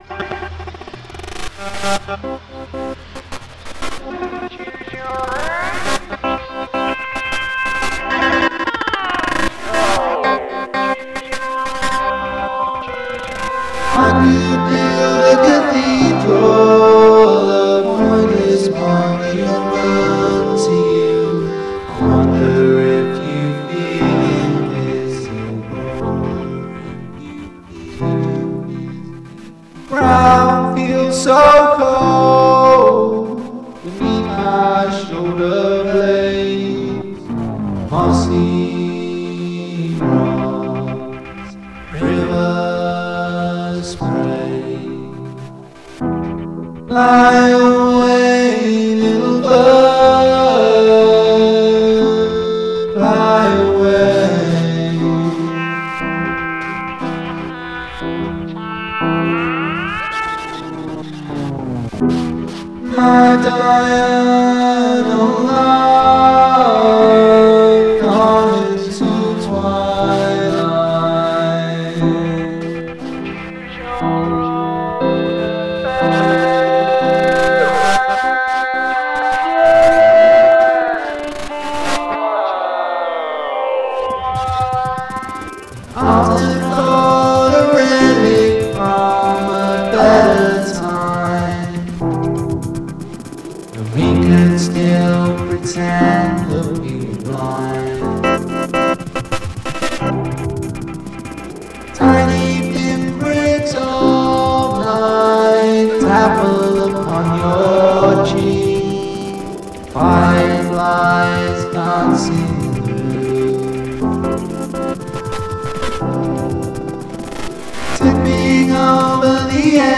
Ska or Glitch (not both)